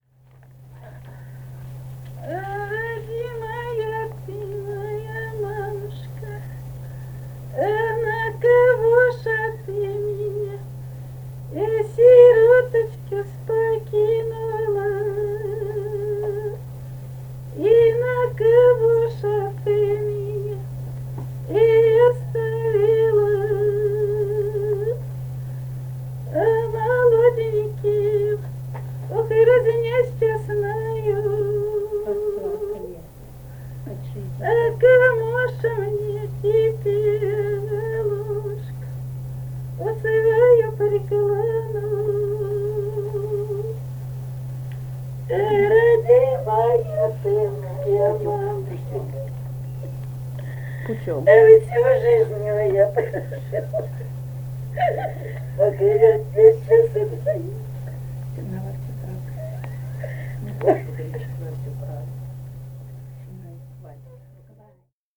полевые материалы
«А родимая ты моя мамушка» (похоронное причитание).
Самарская область, с. Усманка Борского района, 1972 г. И1316-23